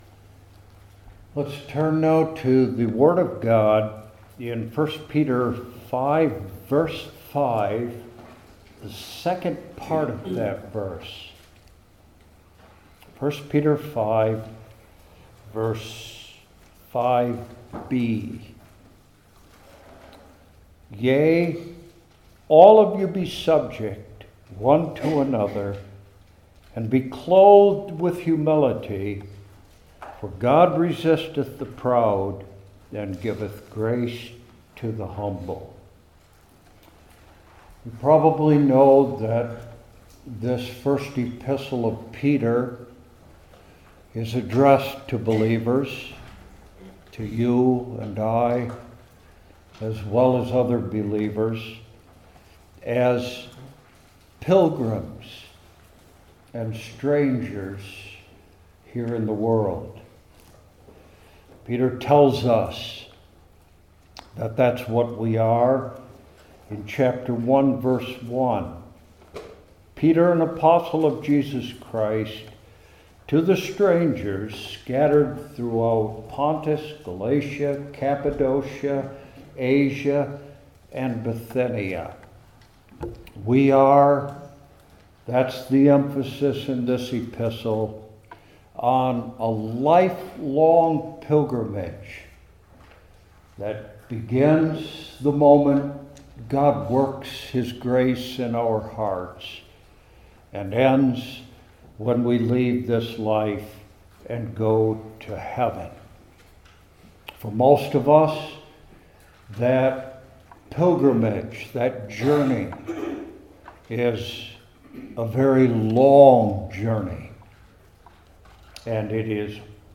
I Peter 5:5 Service Type: New Testament Individual Sermons I. The Clothing Examined II.